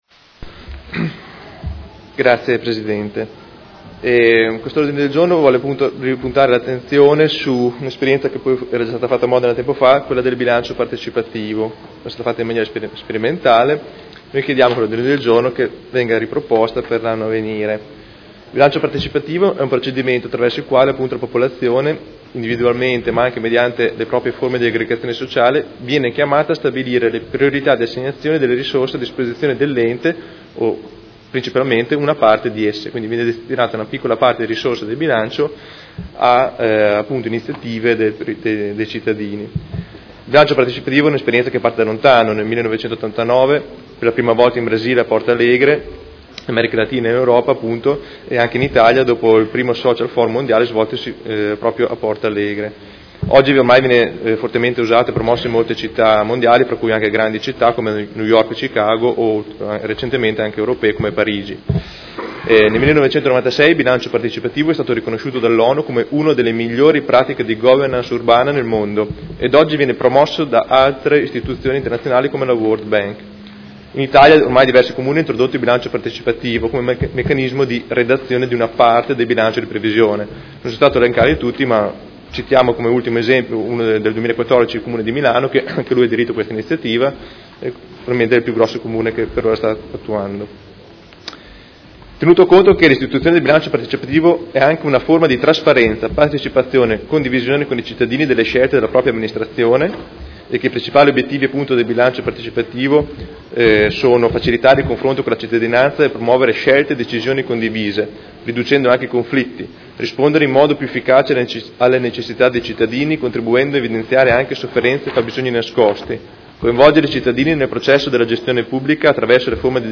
Seduta del 26 gennaio.
Audio Consiglio Comunale